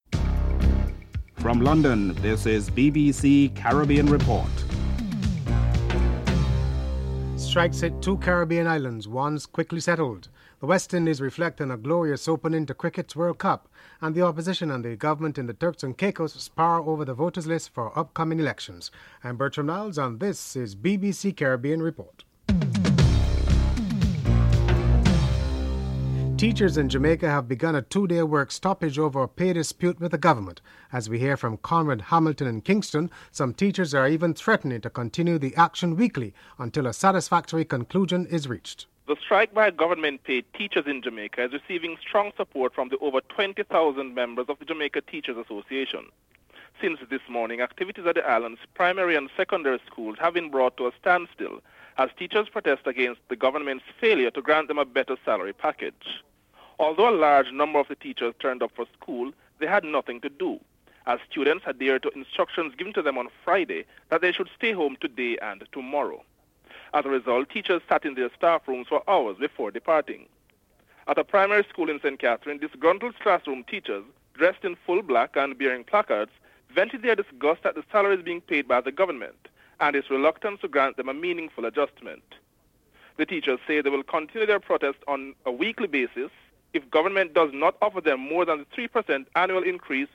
Headlines
Clement Rohee, Guyana’s Minister of Foreign Affairs is interviewed as a member of the ACP delegation that traveled to Brazil for the purpose of lobbying its officials.